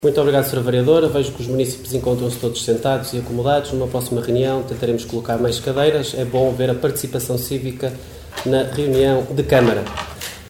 Excertos da última reunião do executivo caminhense, no passado dia 6 de Novembro no Salão Nobre do edíficio dos Paços do Concelho.